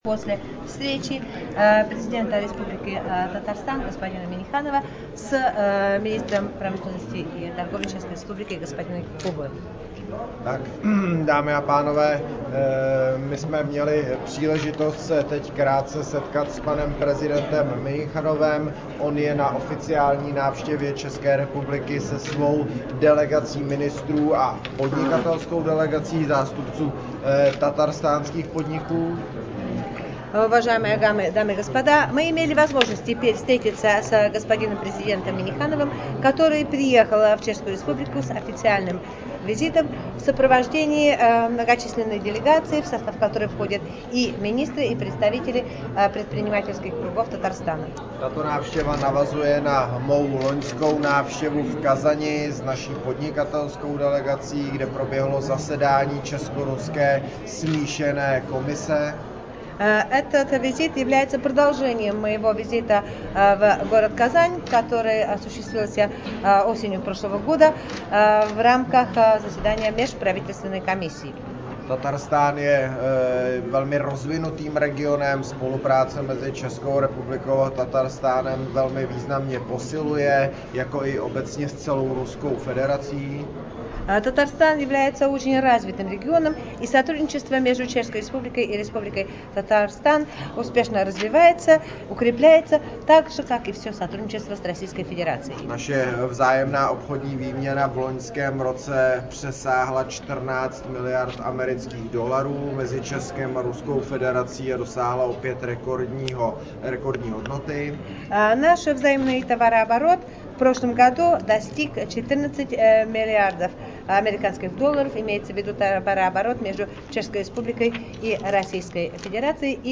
Аудиорепортаж
Открытие Чешско-Татарстанского бизнес-форума с участием Министра промышленности и торговли Чешской Республики М. Куба и Президента Республики Татарстан Р.Н. Минниханова.